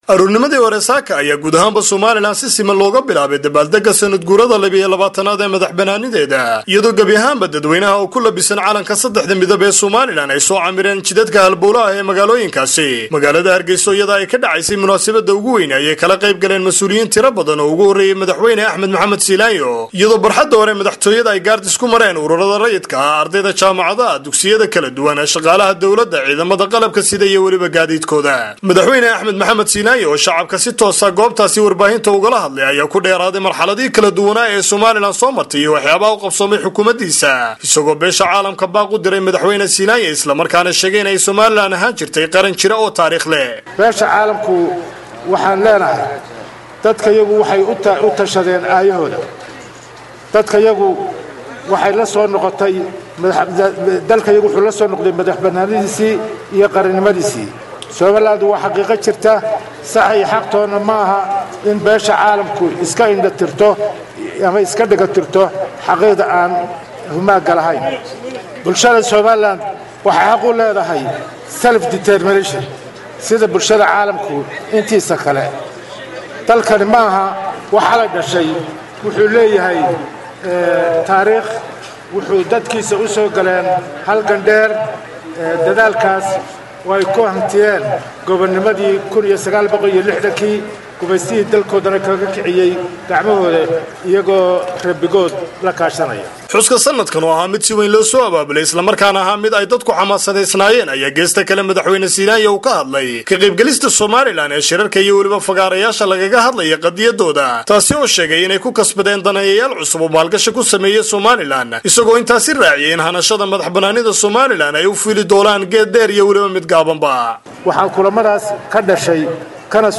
Warbixinta Sanad-guuradii 22aad ee Somaliland